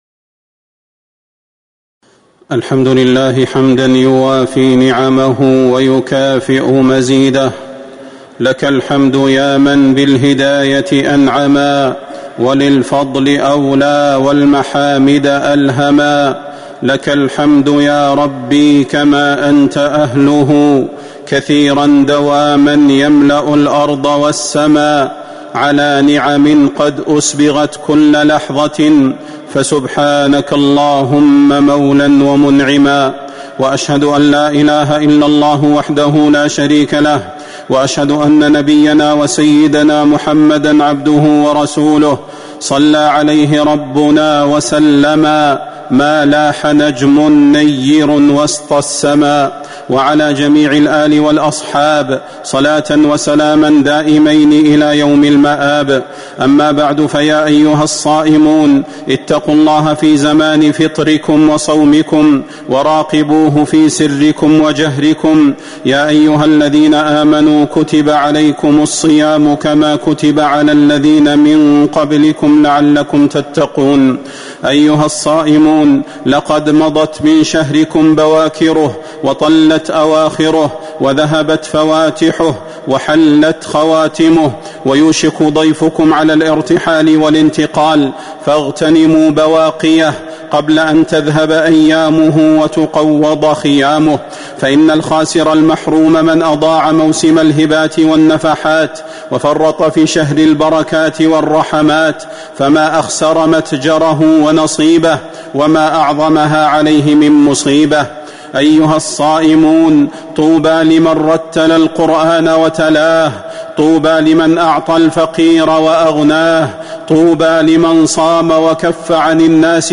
تاريخ النشر ١٩ رمضان ١٤٤٥ هـ المكان: المسجد النبوي الشيخ: فضيلة الشيخ د. صلاح بن محمد البدير فضيلة الشيخ د. صلاح بن محمد البدير وصايا مهمة للصائمين